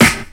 • 90's Hip-Hop Snare Drum Sample B Key 01.wav
Royality free snare drum tuned to the B note. Loudest frequency: 2361Hz
90s-hip-hop-snare-drum-sample-b-key-01-H7Q.wav